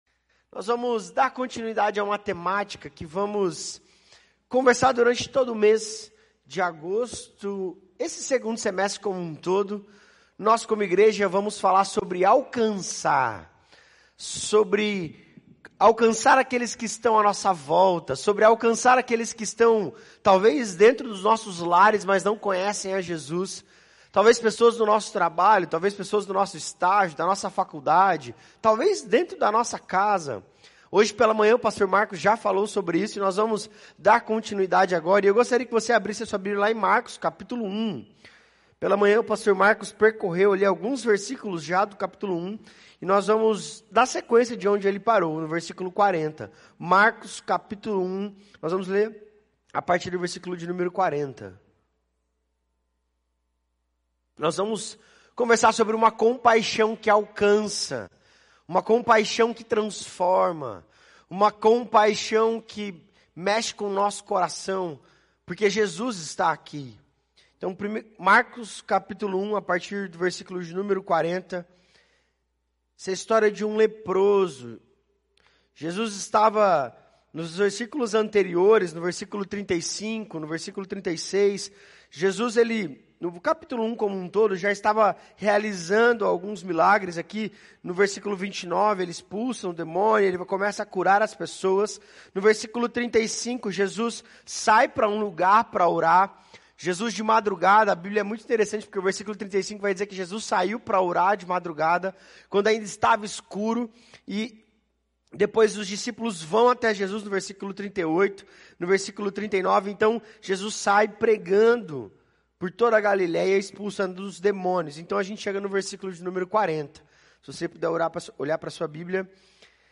Mensagem
na Igreja Batista do Bacacheri